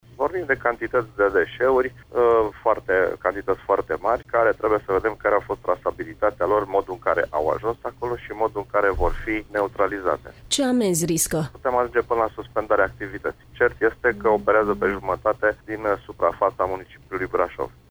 Șeful Comisariatului Judeţean Braşov al Gărzii Naţionale de Mediu Mircea Paraschiv: